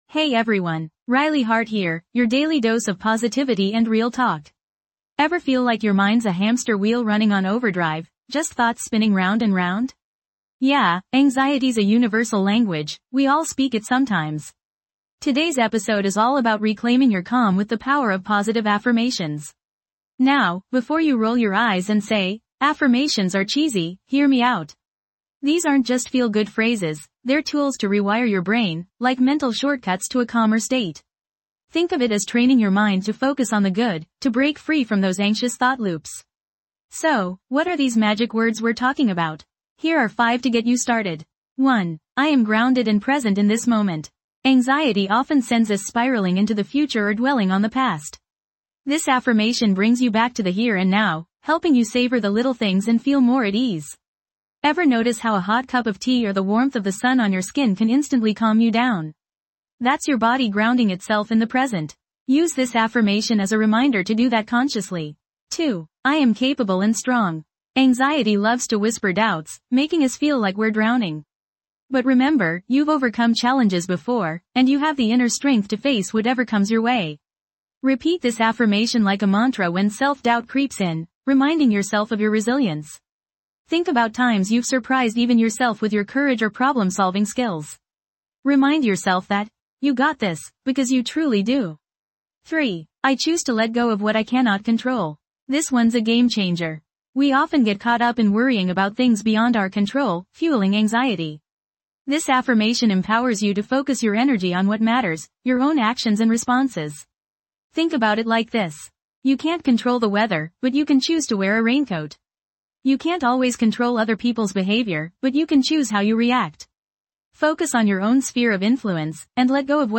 This podcast offers a sanctuary of calm amidst the chaos of everyday life. With gentle affirmations and soothing guidance, we guide you through a 5-minute daily reset, helping you cultivate inner peace, reduce stress, and find moments of tranquility throughout your day.